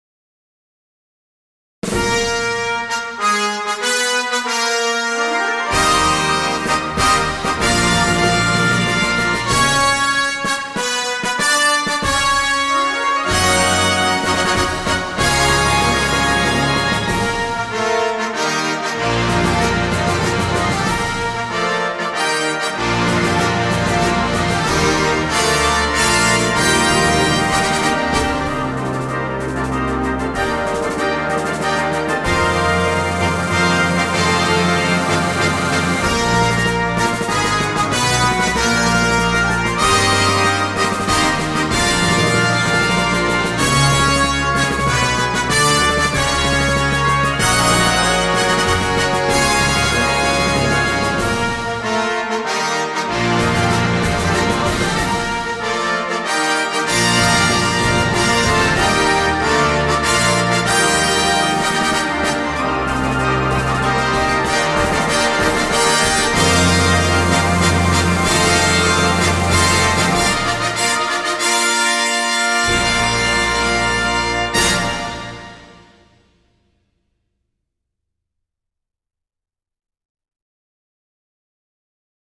CategoryConcert Band
InstrumentationPiccolo
Trumpets 1-2-3-4
Timpani
Xylophone